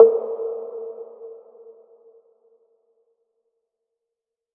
OZ - Perc 1.wav